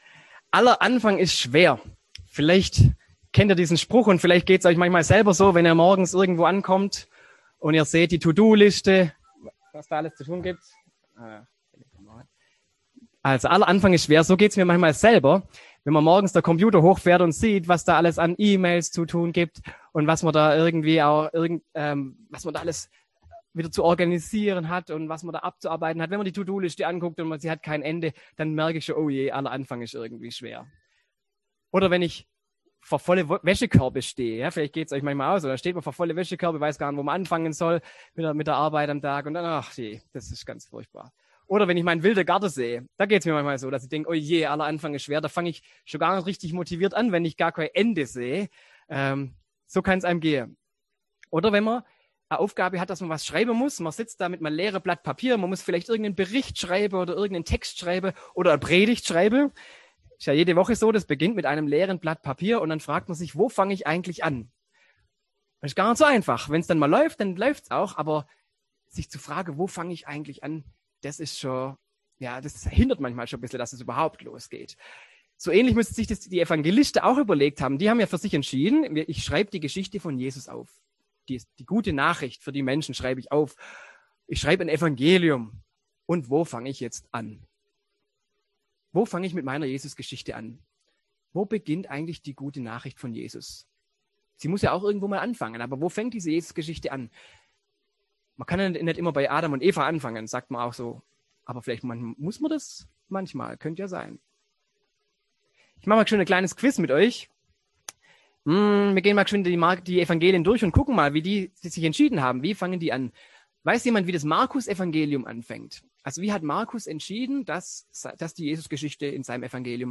Predigt am 1. Advent
im Online-Gottesdienst